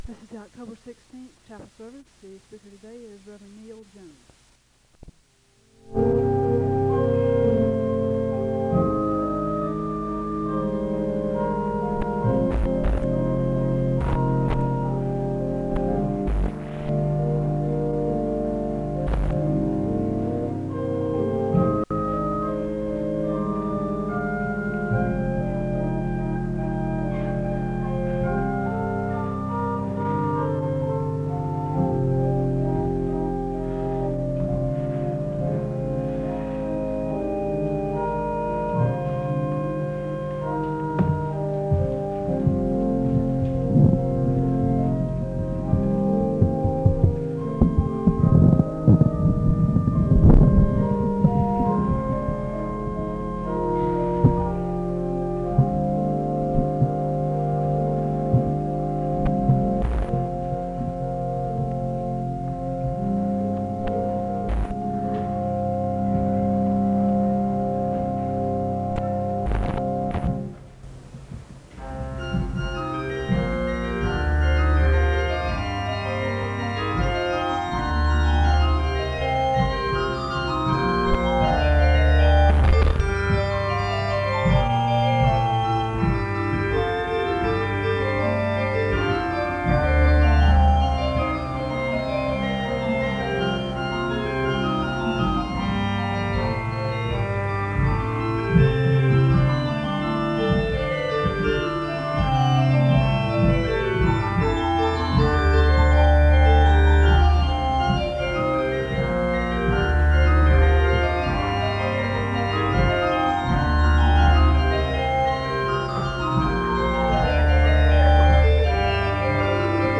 The service begins with organ music (00:00-07:00).
The choir sings the anthem (12:57-15:20).
Resource type Audio Citation Archives and Special Collections, Library at Southeastern, Southeastern Baptist Theological Seminary, Wake Forest, NC.